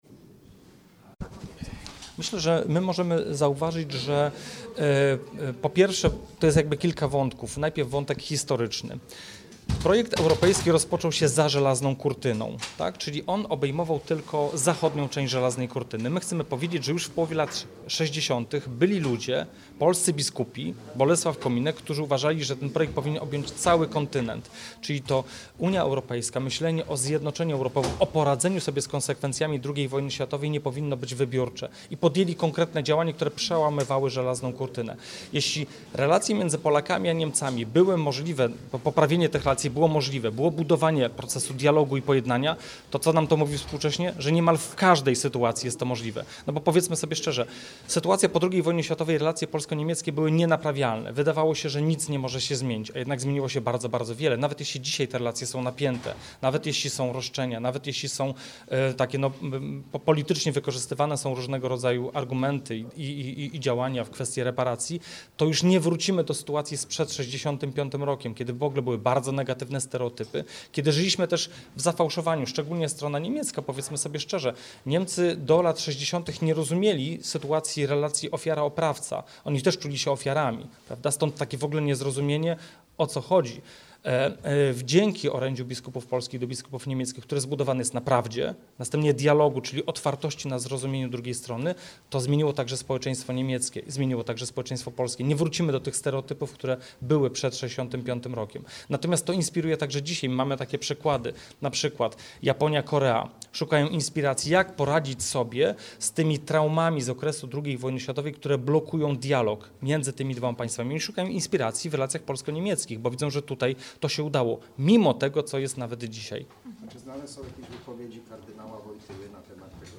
Z okazji 60. rocznicy orędzia biskupów polskich do niemieckich w gmachu Muzeum Archidiecezjalnego we Wrocławiu odbyła się konferencja „Odwaga wyciągniętej ręki”.